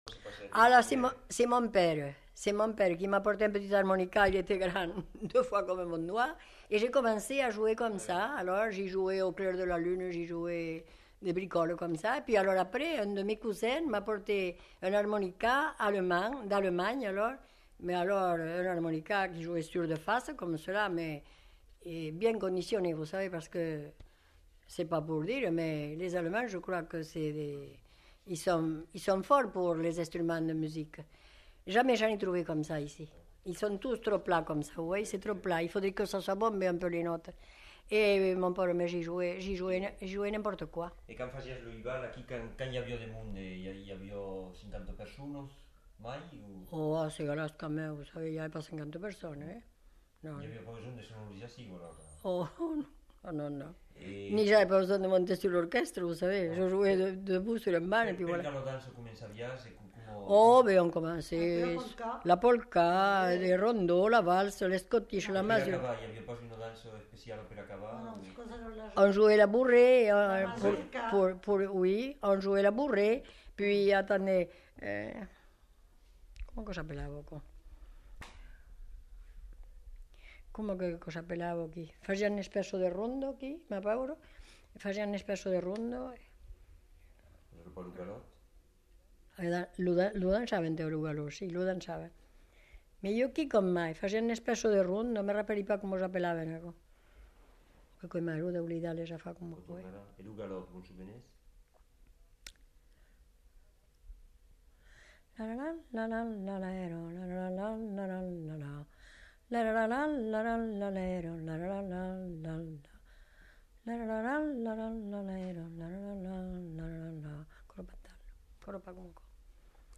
Aire culturelle : Haut-Agenais
Lieu : Cancon
Genre : récit de vie